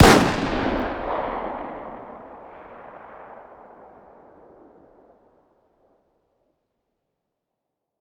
fire-dist-357sig-pistol-ext-02.ogg